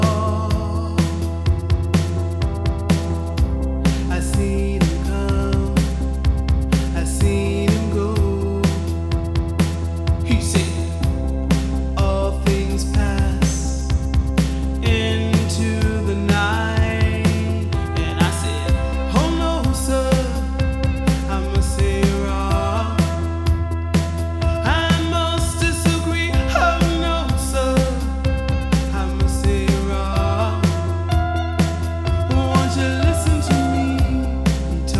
New Wave Alternative Rock
Жанр: Рок / Альтернатива